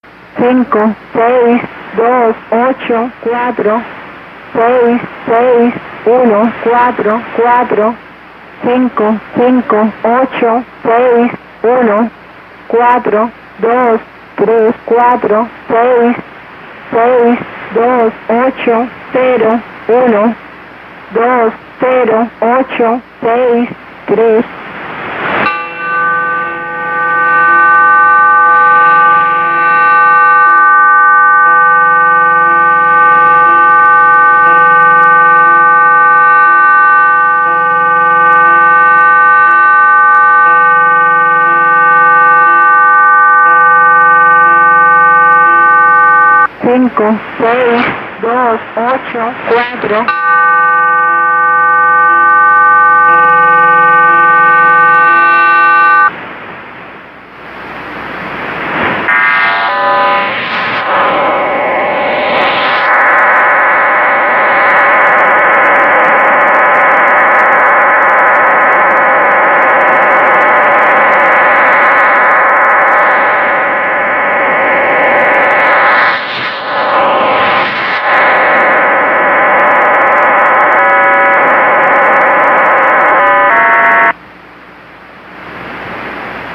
Estacion_de_números_HM01.ogg